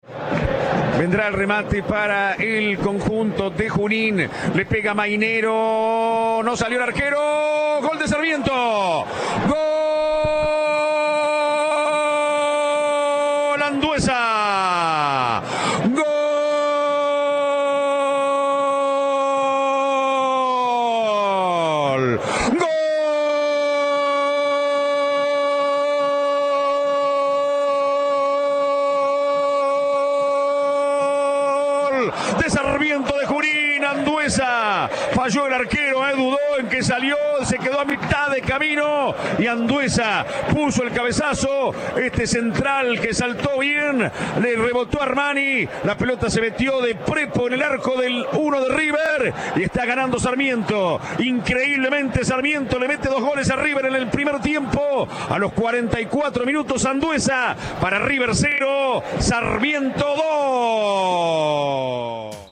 Reviví las narraciones de los goles en la noche de Núñez